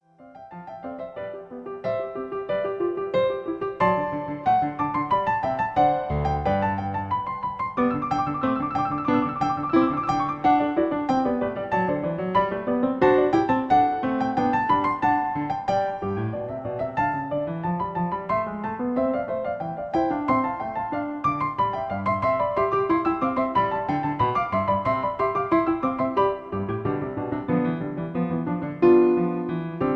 Original Key. Piano Accompaniment